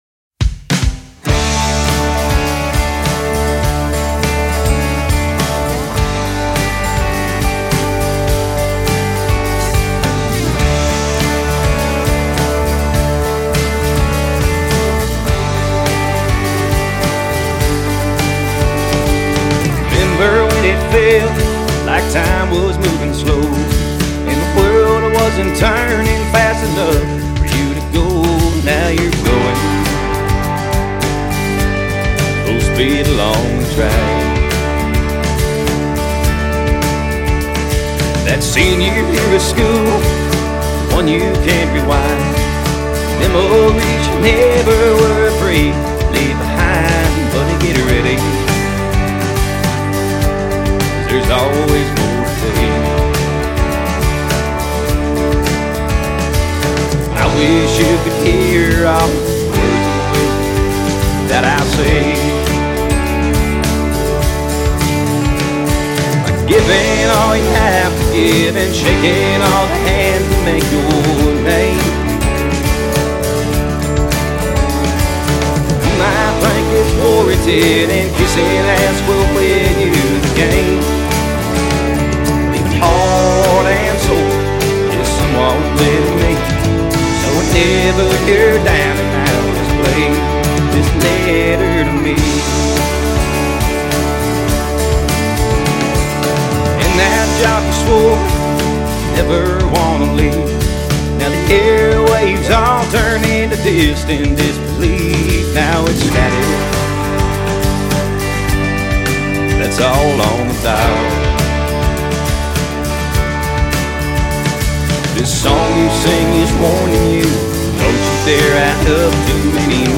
Genre Country